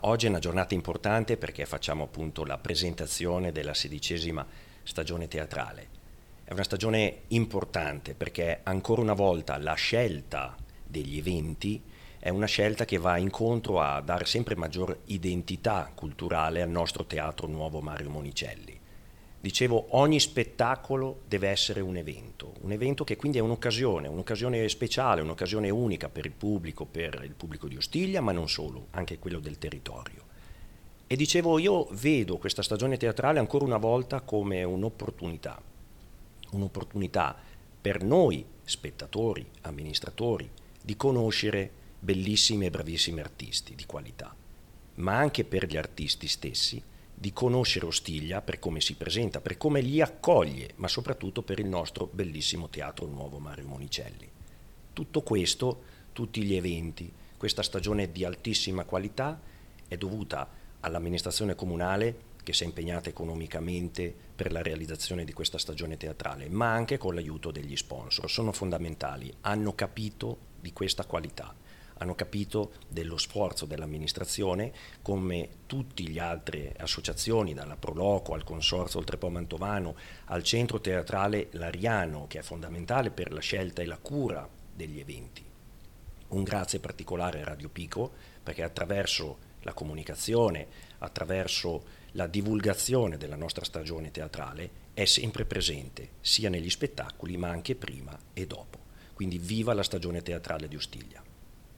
Le parole del Sindaco Valerio Primavori:
sindaco-ostiglia.mp3